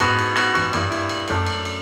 keys_18.wav